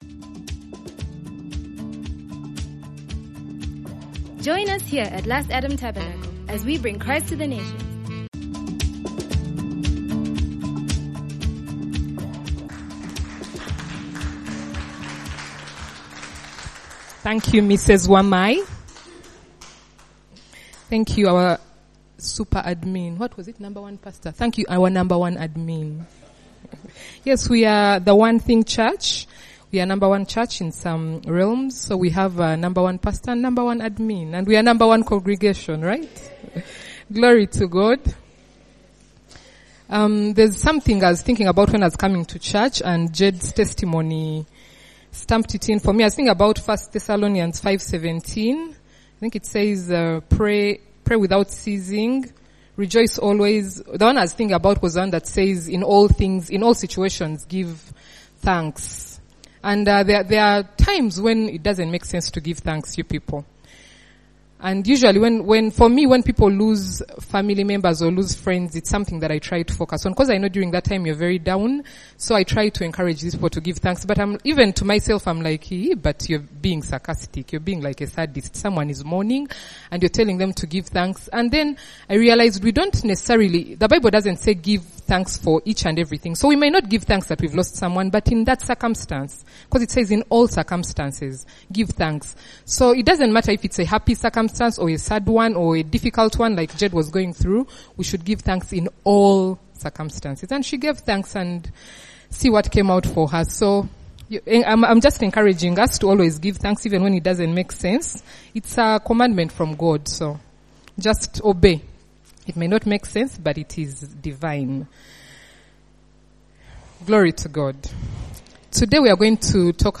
Prayer is necessary for us Christians as we grow into the image of Jesus Christ. In this sermon, I share tips on how we can improve this aspect of our walk.